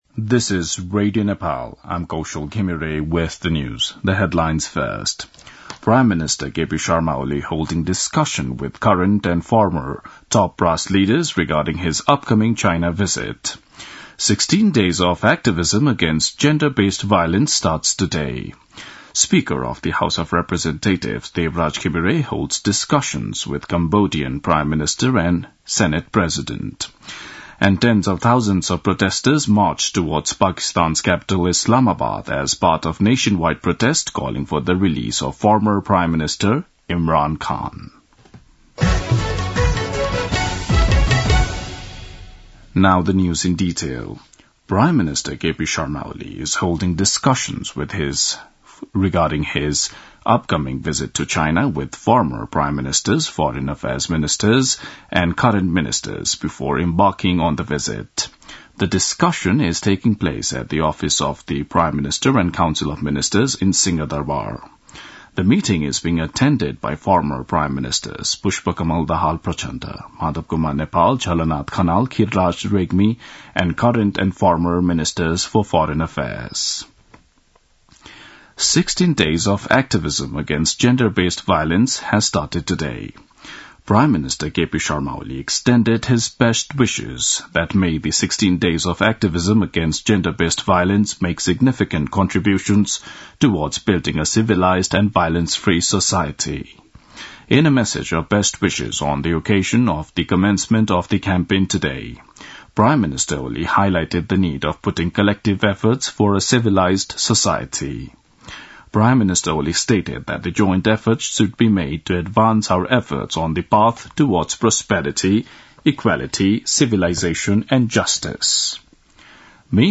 दिउँसो २ बजेको अङ्ग्रेजी समाचार : ११ मंसिर , २०८१
2-pm-english-news-1-10.mp3